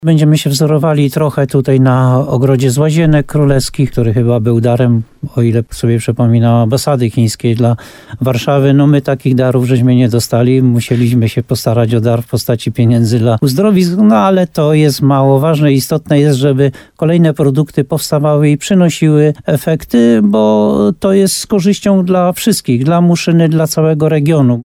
Jak zapowiada burmistrz Muszyny Jan Golba, jeden z nich będzie nawiązywał do kultury chińskiej.